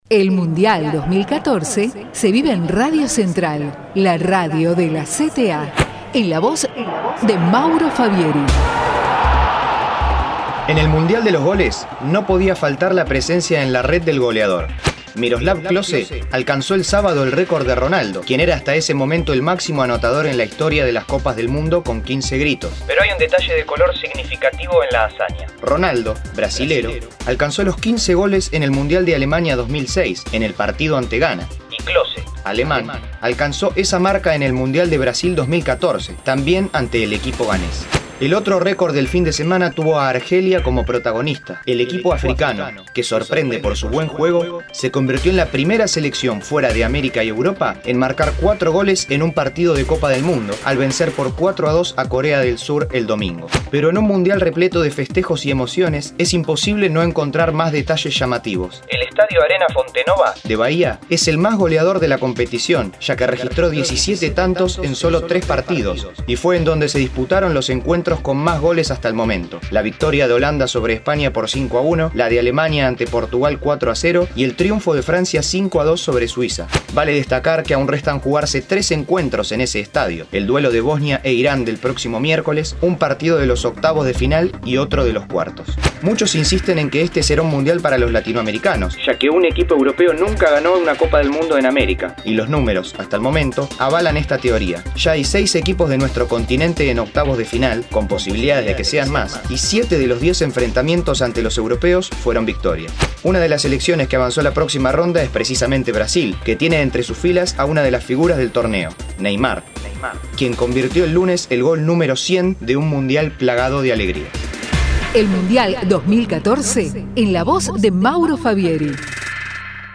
MUNDIAL BRASIL 2014: Micro-Informativo RADIO CENTRAL
mundial_micro_03_radiocentral.mp3